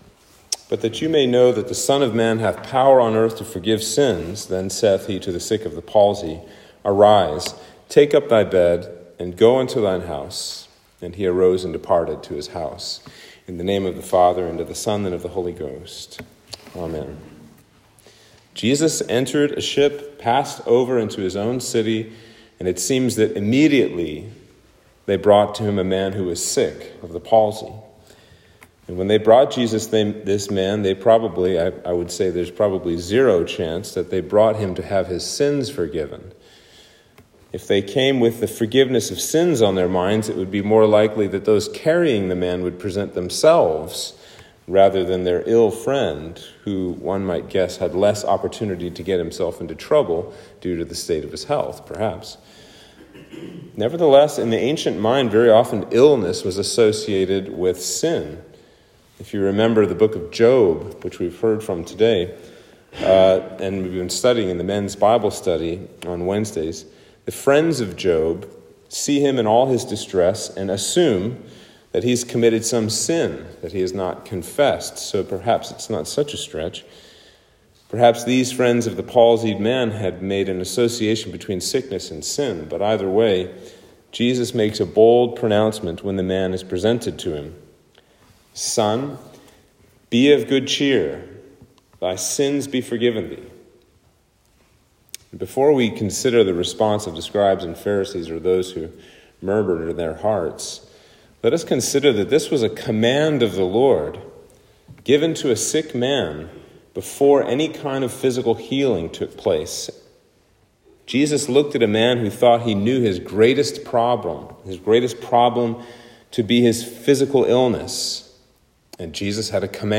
Sermon for Trinity 19